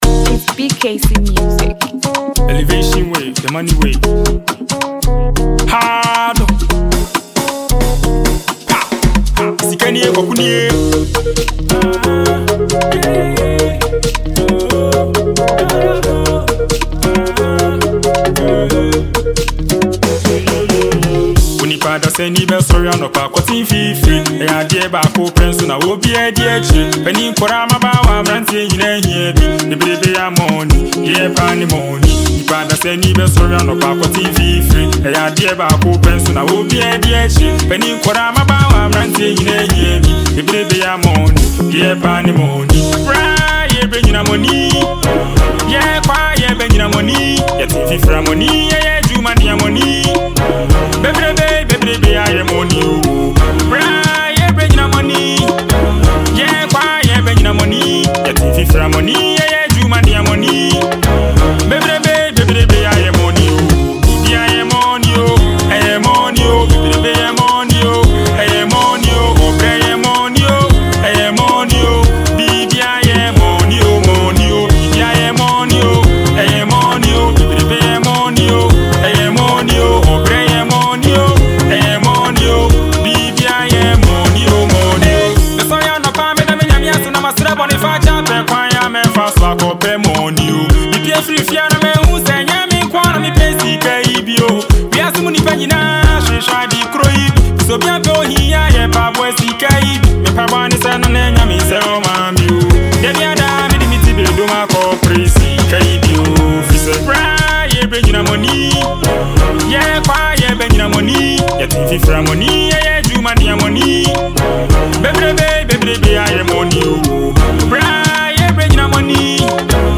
a Ghanaian artist
and this is a solo tune.
jam tune